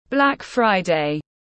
Ngày thứ 6 đen tối tiếng anh gọi là Black Friday, phiên âm tiếng anh đọc là /ˌblæk ˈfraɪ.deɪ/
Black Friday /ˌblæk ˈfraɪ.deɪ/